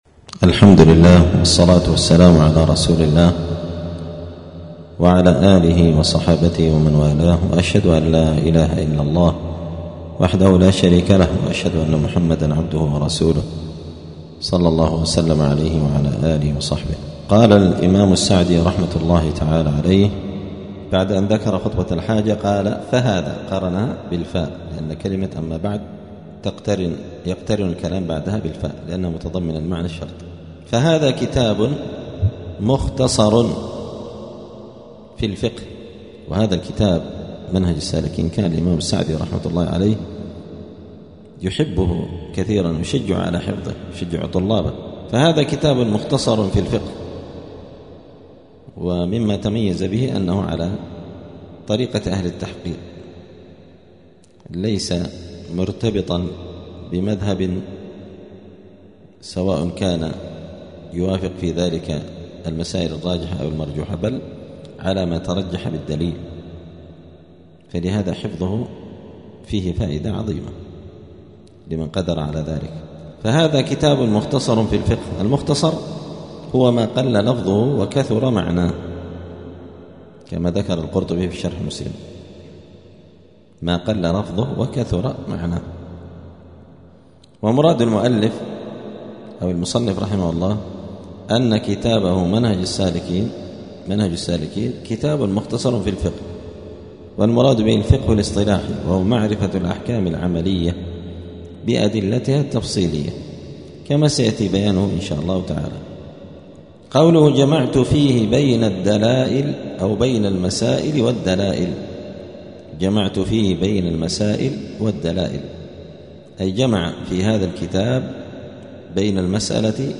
*الدرس الخامس (5) {أنواع التقليد}*
دار الحديث السلفية بمسجد الفرقان قشن المهرة اليمن